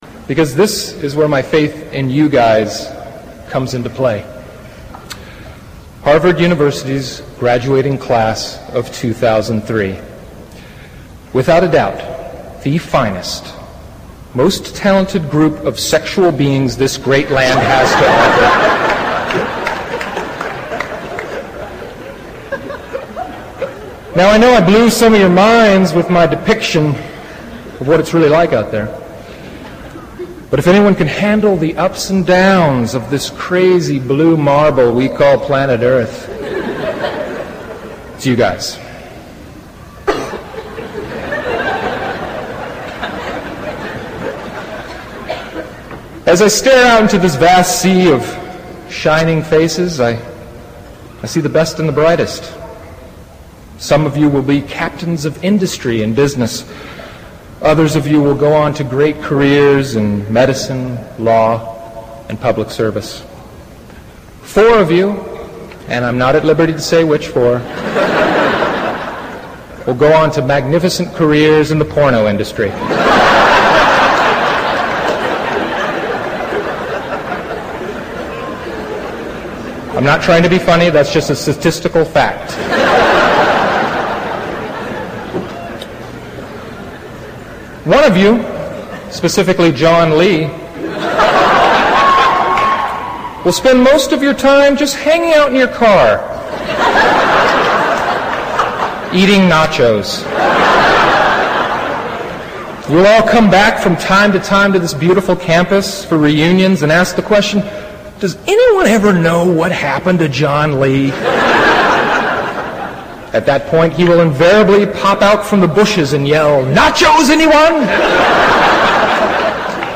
Full Speech part 3
Tags: Class of 2003 Will Ferrell Harvard commencement speech audio clip